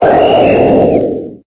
Cri de Type:0 dans Pokémon Épée et Bouclier.